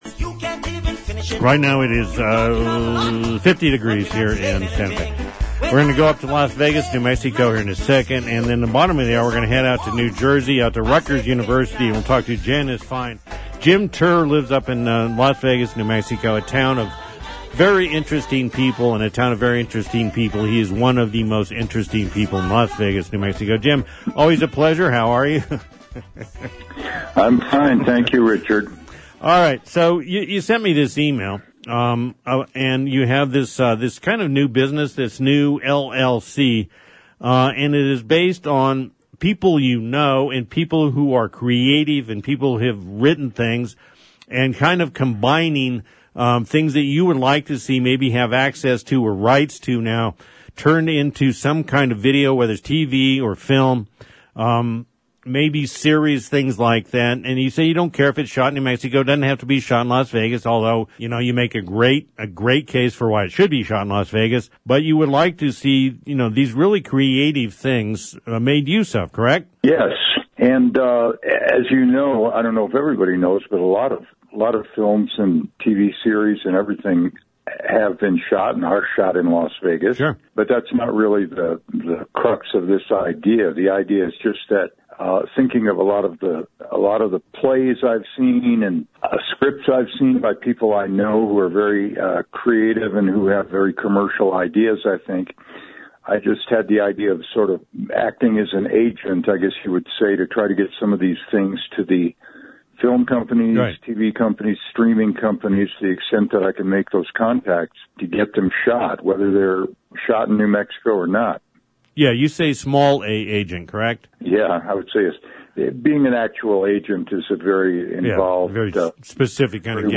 HEAR INTERVIEW ABOUT PROJECT ON KTRC/SANTA FE 2-6-24